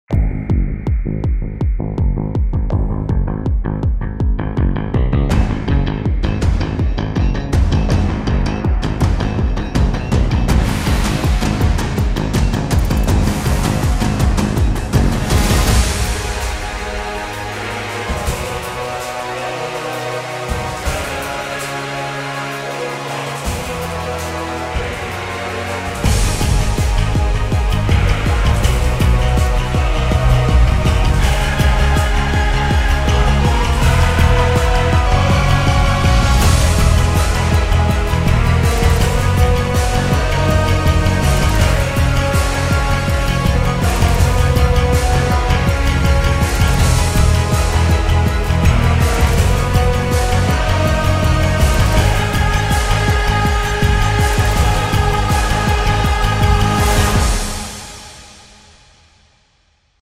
orchestral epic cinematic